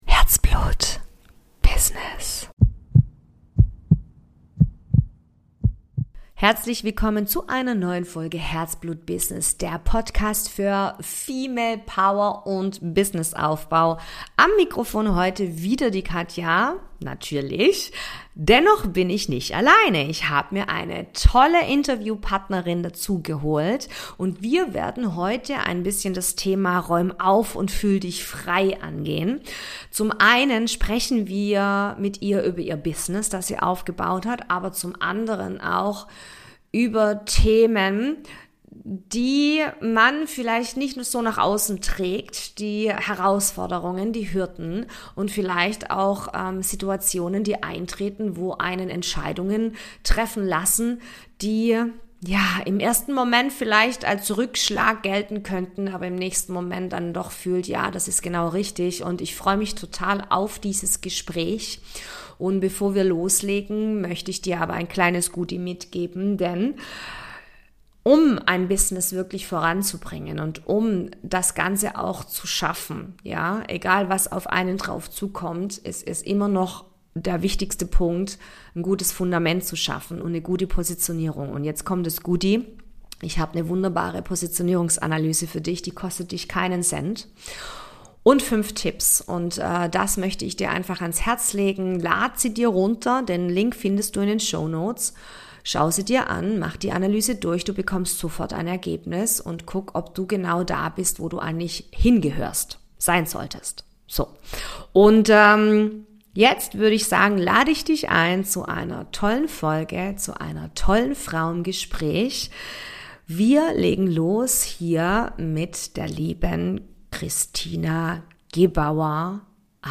#56 Räume auf und fühl dich frei - Interview